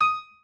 pianoadrib1_30.ogg